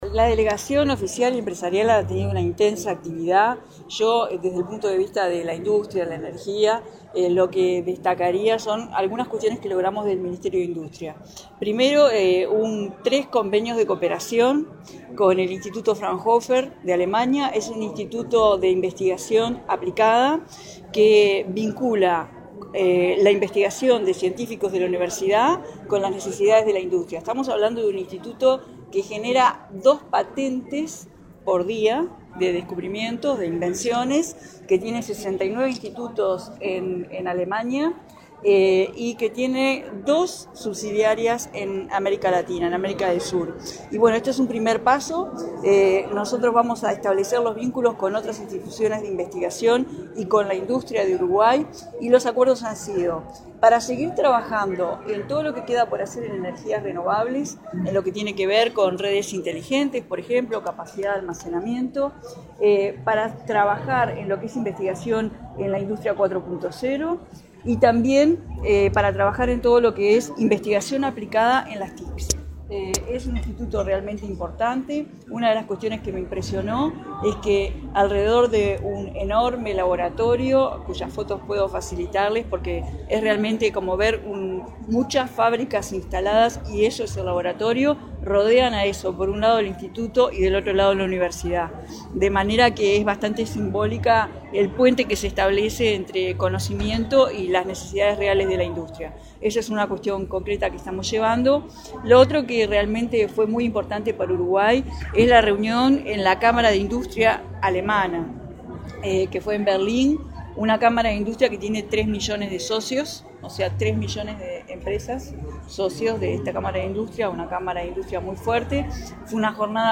La ministra de Industria, Carolina Cosse, repasó las actividades que tuvo la delegación oficial y empresarial esta semana en Alemania. En diálogo con la prensa uruguaya, detalló los convenios de cooperación que firmó con diversas instituciones alemanas para trabajar en energías renovables, industria 4.0 e investigación aplicada en las TIC.